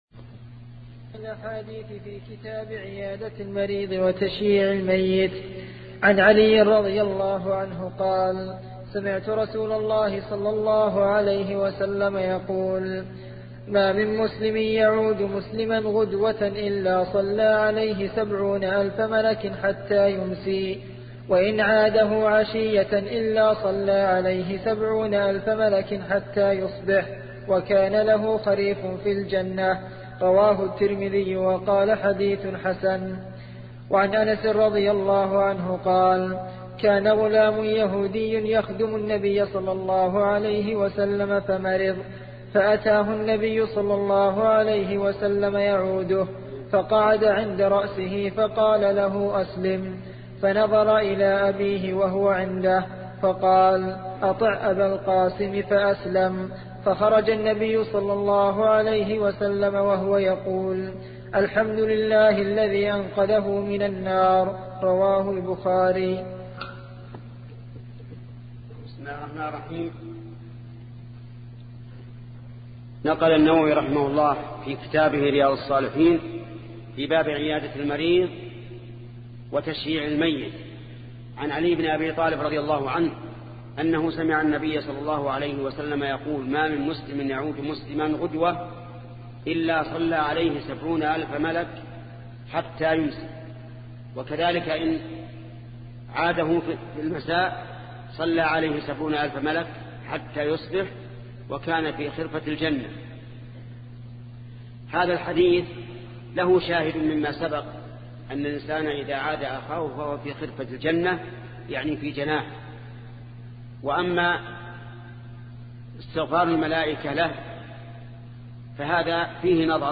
سلسلة مجموعة محاضرات شرح رياض الصالحين لشيخ محمد بن صالح العثيمين رحمة الله تعالى